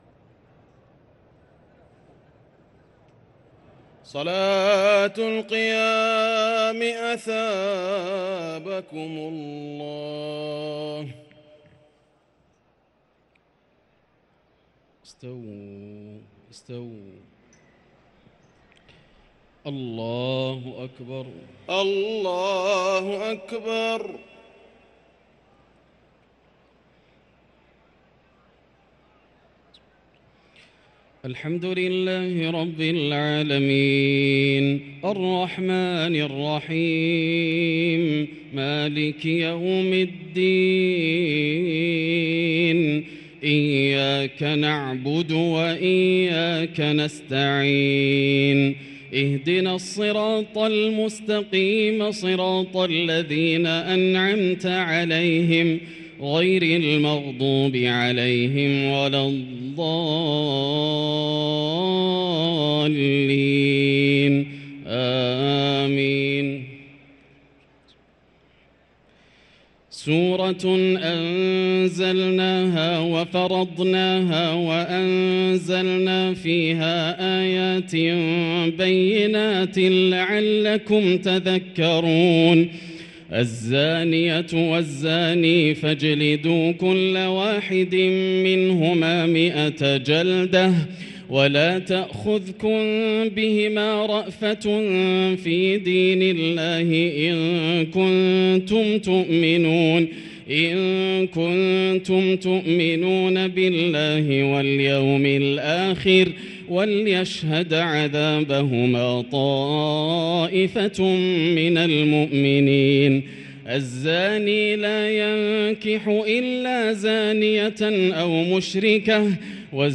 صلاة التراويح ليلة 22 رمضان 1444 للقارئ ياسر الدوسري - الثلاث التسليمات الاولى صلاة التهجد